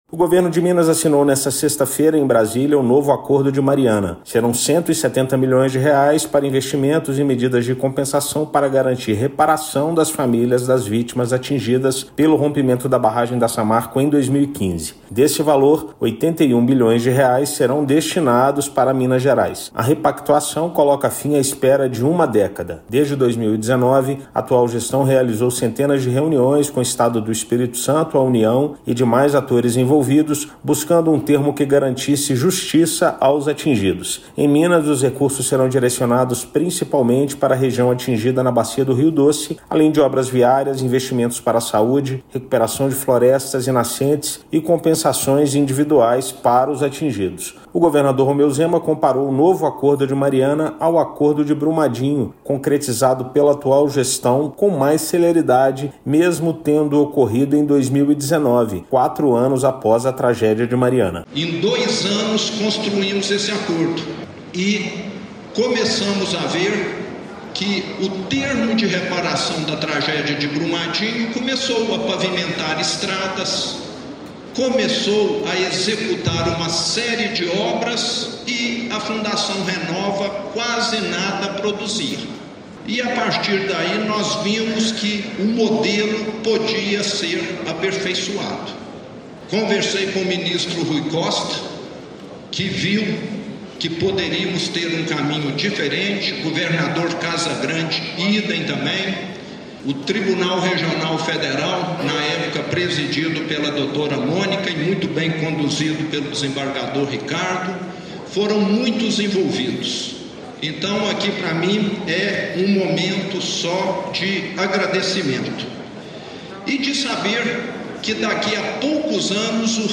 O território mineiro terá direito a mais de 60% dos R$ 132 bilhões previstos. Desse valor, 96% serão destinados à Bacia do Rio Doce. Ouça matéria de rádio.